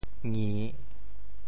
「睨」字用潮州話怎麼說？
睨 部首拼音 部首 目 总笔划 13 部外笔划 8 普通话 nì 潮州发音 潮州 ngi3 文 中文解释 睨〈動〉 (形聲。